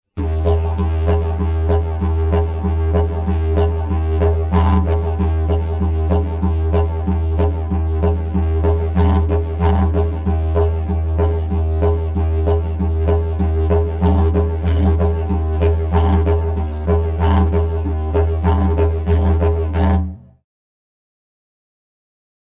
9.3 Rolling
Sample n°15 contiene: applicazione del rolling su nota base.